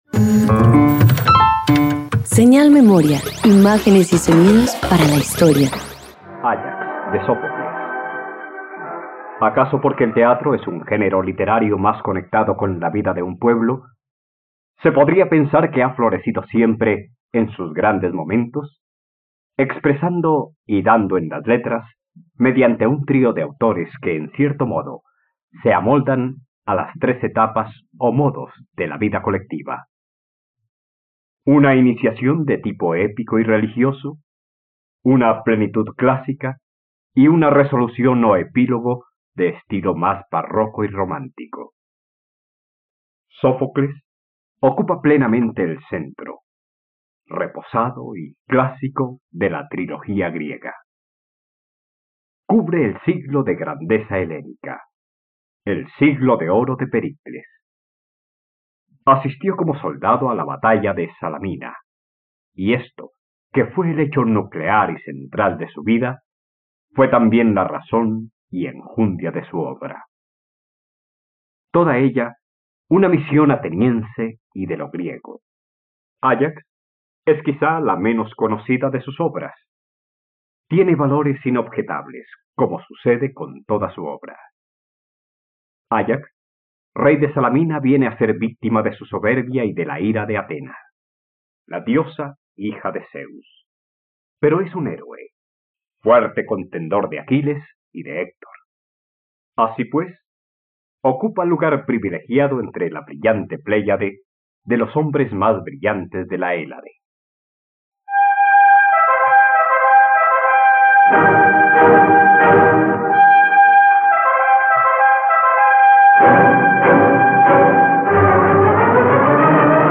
..Radioteatro. Escucha la adaptación radiofónica de “Áyax” de Sófocles de Ghelderode por la plataforma streaming RTVCPlay.